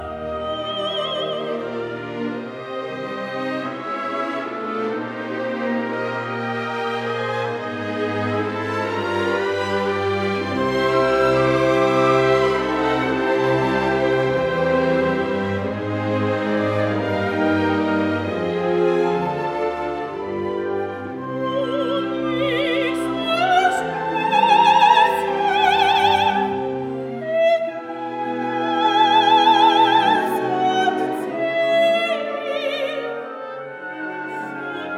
Classical Orchestral
Жанр: Классика